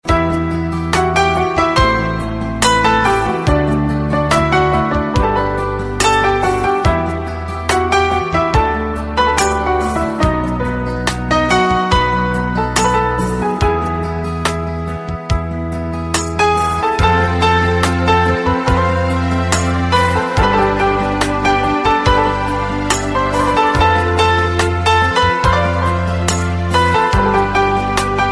Message Tones